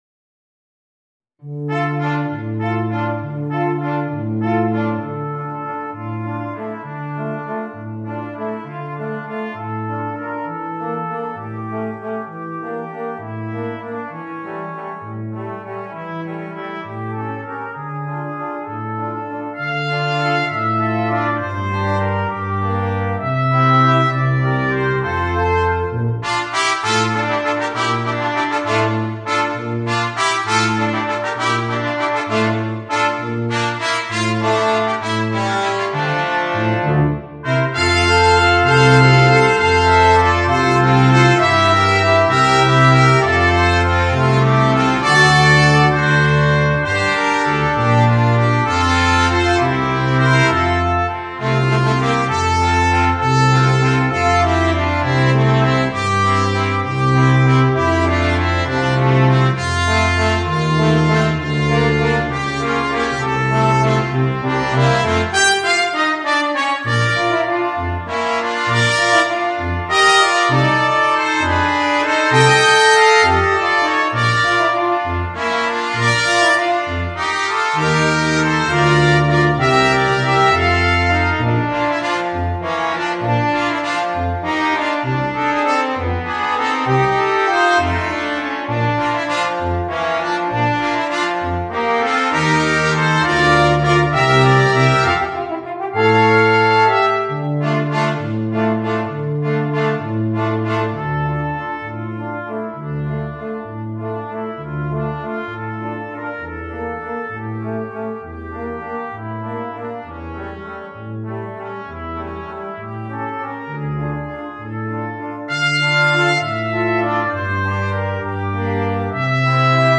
Voicing: Brass Quartet and Rhythm